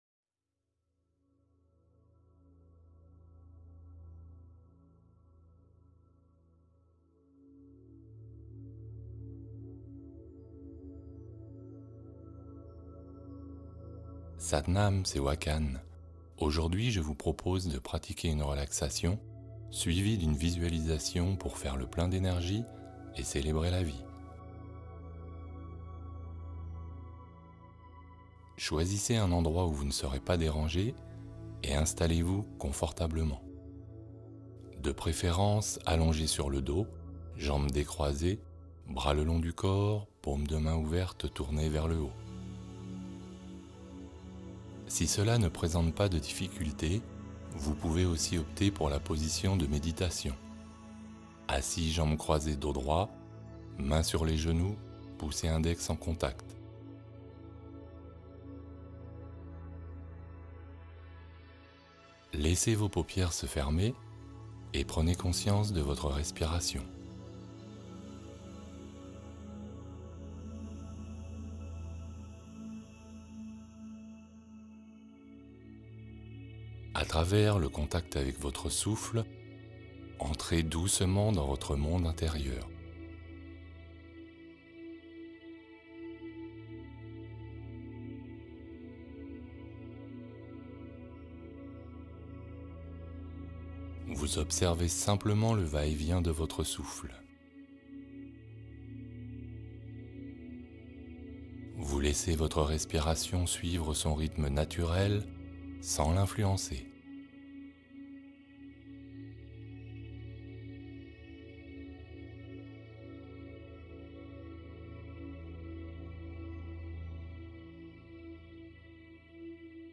Bols tibétains : purifier les peurs et ouvrir l’énergie